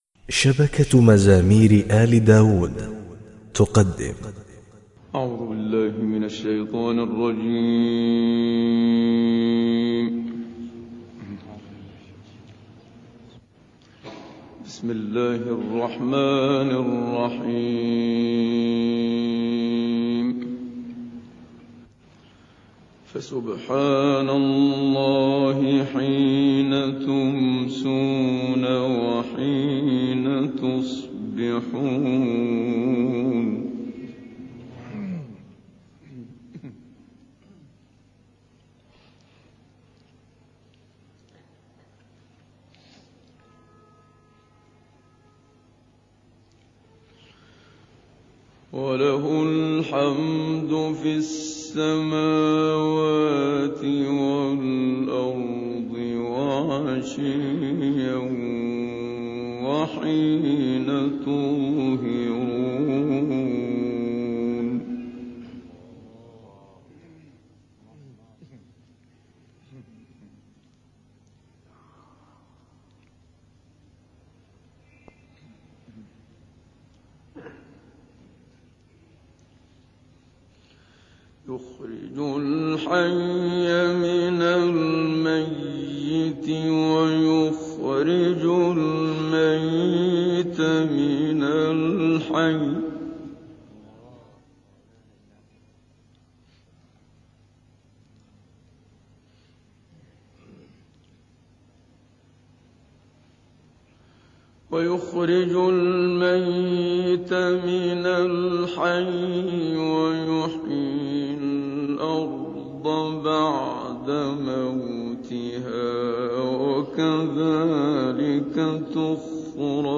تسجيلات الكويت للشيخ محمد صديق المنشاوي